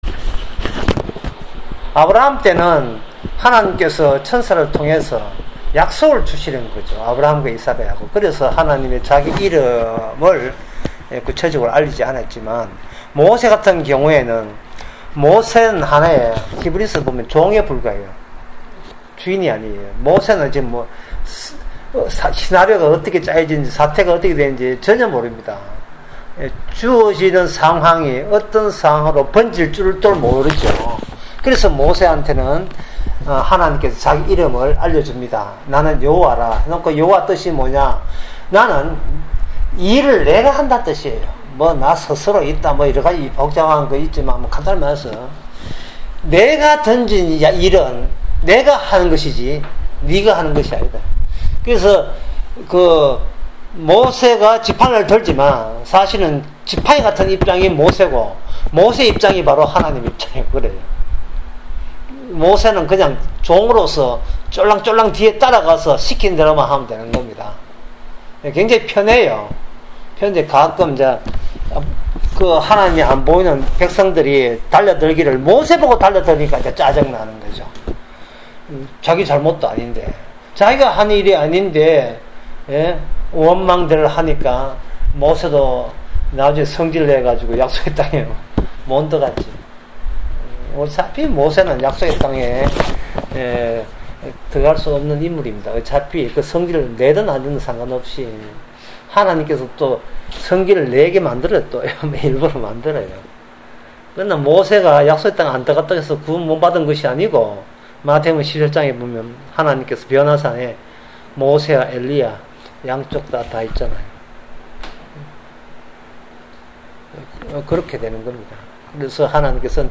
구약 설교, 강의